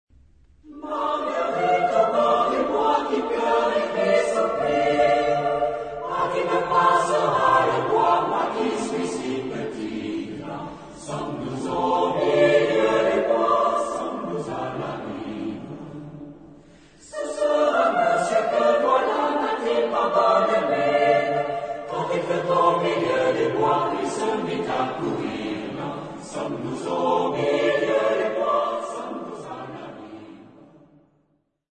Genre-Style-Form: Secular ; Popular ; Song with repetition
Mood of the piece: lively ; playful
Type of Choir: SATB  (4 mixed voices )
Tonality: D minor
Origin: Akkadia ; Canada